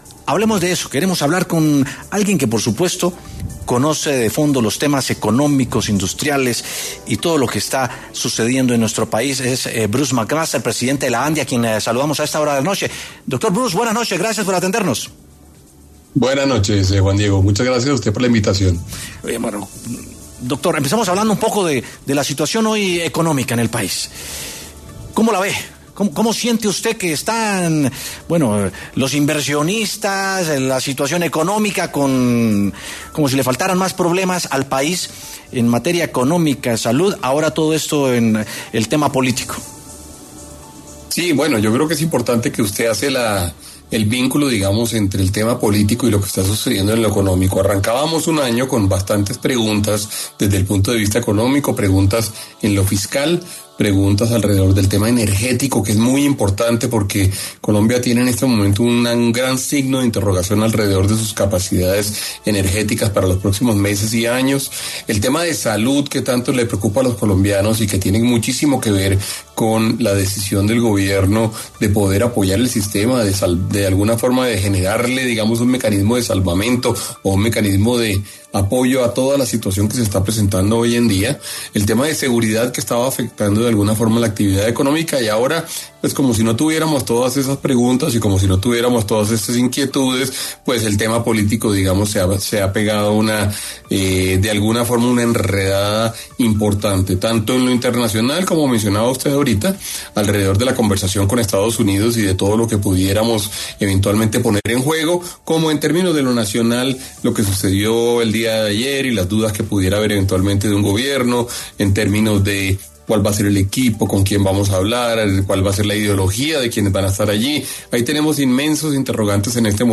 El presidente de la Asociación Nacional de Industriales (ANDI), Bruce Mac Master, pasó por los micrófonos de W Sin Carreta para exponer sus perspectivas sobre el tema desde su gremio económico.